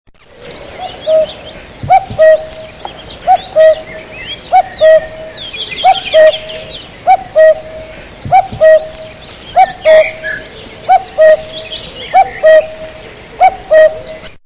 Our clocks don't just tell time, they bring it to life with charming hourly sounds.
Click on any clock below to hear the sound it makes every hour and discover which one fits your style best.